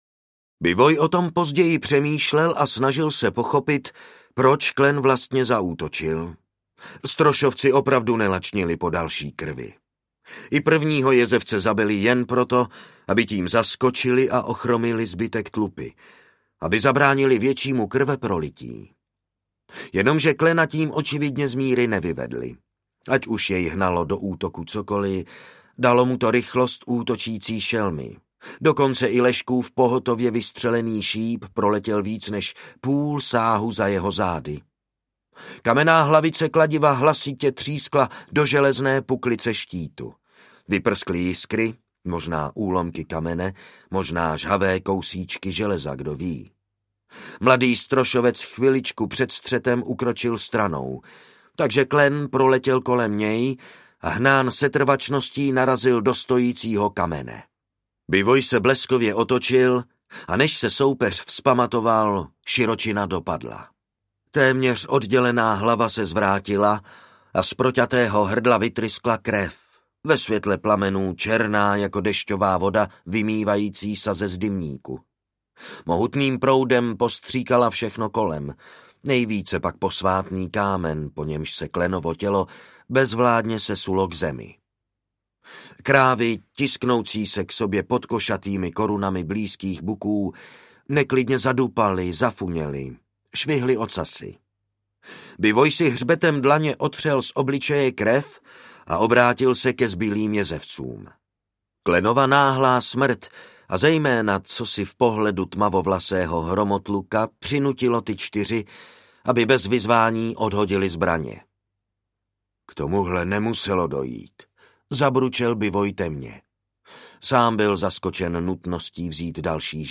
Vládce stříbrného šípu audiokniha
Ukázka z knihy
Vyrobilo studio Soundguru.